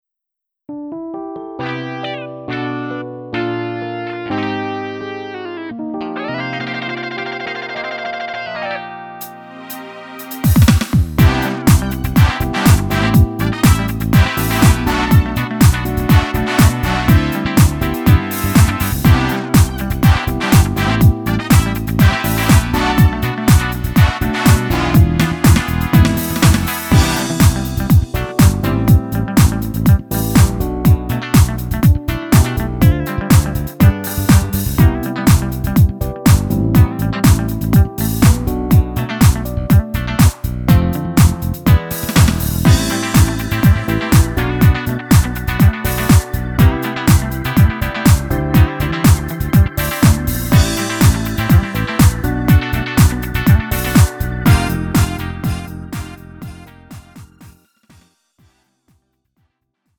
음정 -1키 3:58
장르 가요 구분 Lite MR
Lite MR은 저렴한 가격에 간단한 연습이나 취미용으로 활용할 수 있는 가벼운 반주입니다.